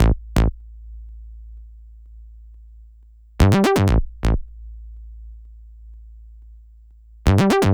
TI124BASS1-L.wav